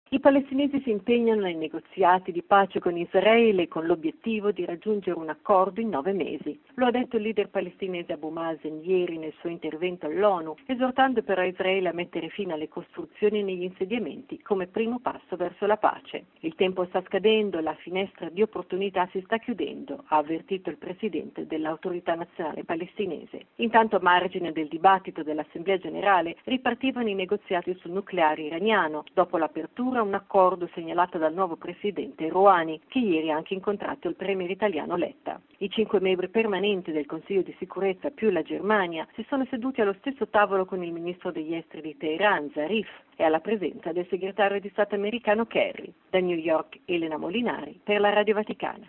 Da New York